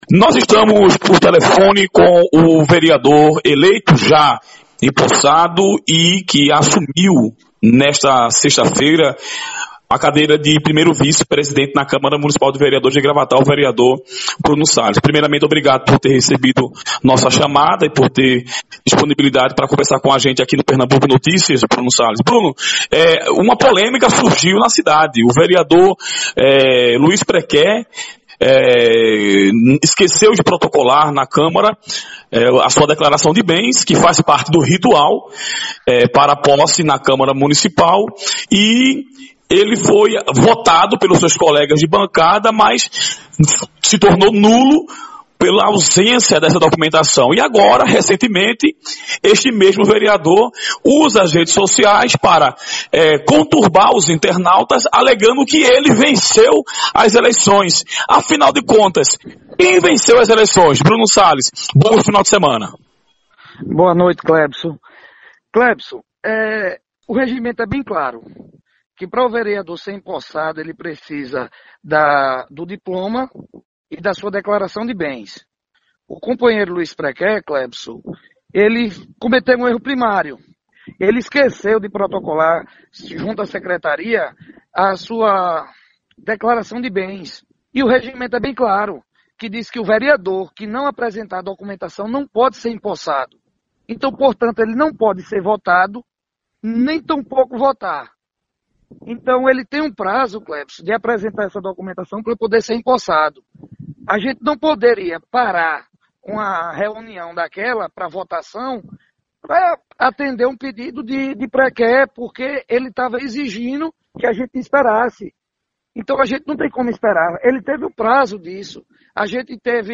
O vereador e 1º vice-presidente da Câmara Municipal de Gravatá, Bruno Sales (PSDB), concedeu entrevista exclusiva ao PERNAMBUCO NOTÍCIAS e destacou que o presidente da Câmara, Léo do AR (PSDB), cumpriu o regimento interno ao não empossar o colega vereador Luiz Prequé (PSD), que deixou de protocolar declaração de bens, contrariando o artigo 14 do Regimento Interno do Poder Legislativo Municipal.